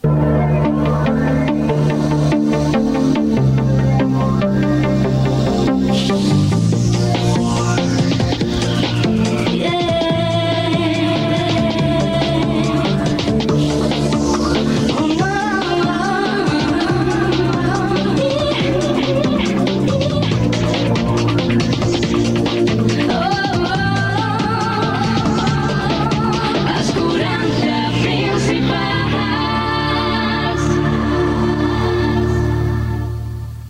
Música i indicatiu
FM